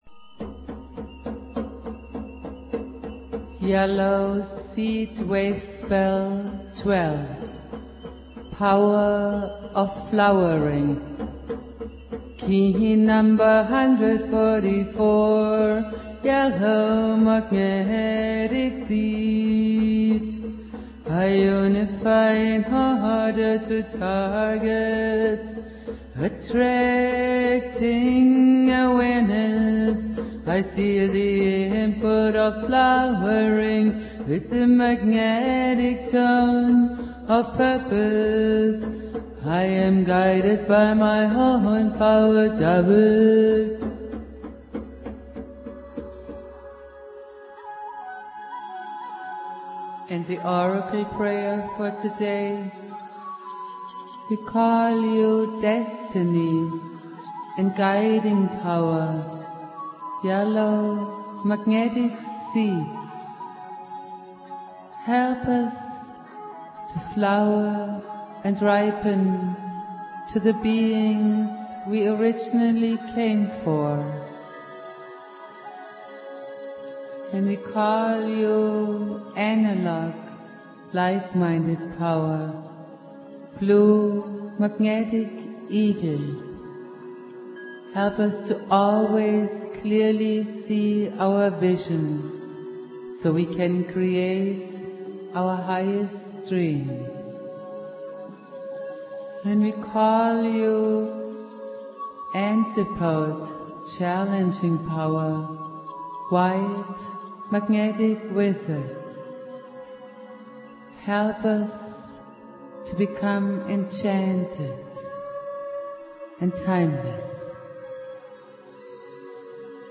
Prayer
playing flute
produced at High Flowing Recording Studio.